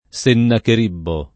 vai all'elenco alfabetico delle voci ingrandisci il carattere 100% rimpicciolisci il carattere stampa invia tramite posta elettronica codividi su Facebook Sennacherib [ S ennaker & b ] (lett. Sennacheribbo [ S ennaker & bbo ]) pers. m. stor.